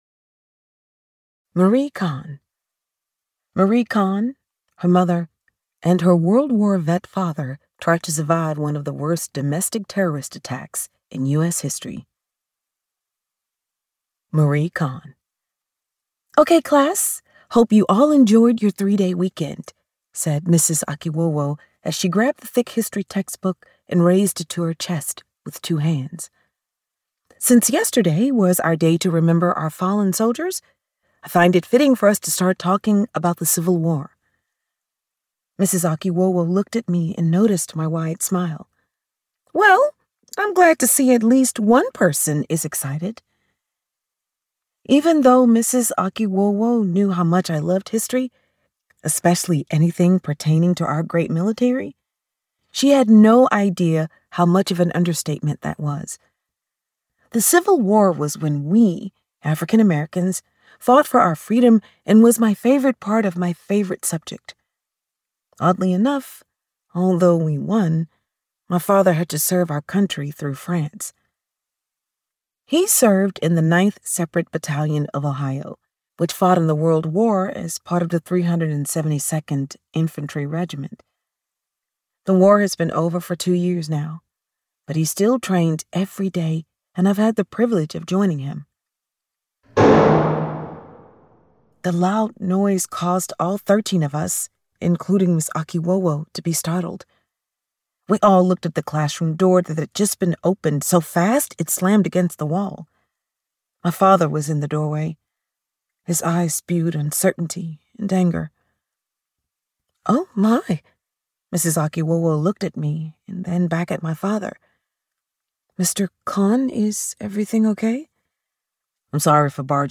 • Audiobook • 0 hrs, 49 mins
Genre: History / Social Themes, Immersive Audio Experience